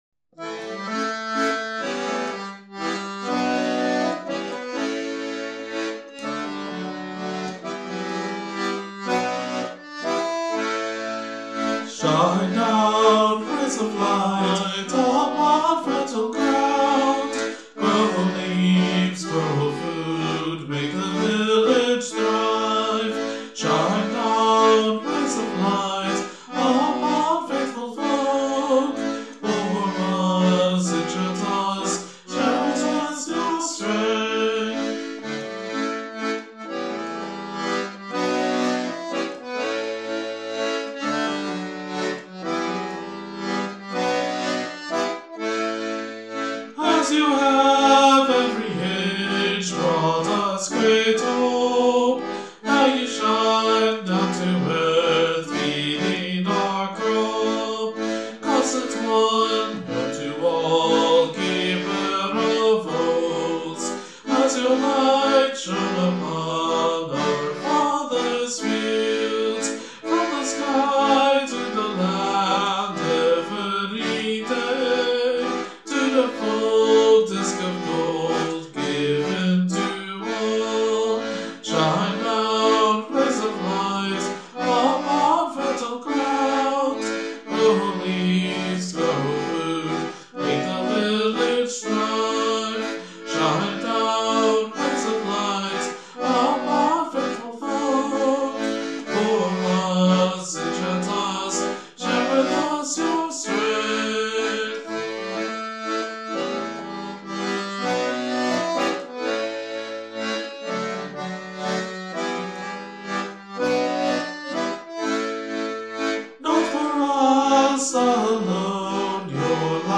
Musically, this achieves some sense of the unusual by using the A mixolydian mode.
This also shifts the dominent harmony from E major to E minor, which softens the ends of each phrase and creates that really odd Bm-Em-A progression used several times in this. The choice of a 3/4 time with an emphasis on the first beat is taken from some Scandanavian dance forms that use this kind of feel, although it's not strictly followed by any means.